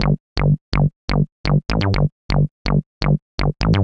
cch_bass_loop_dxorg_125_Am.wav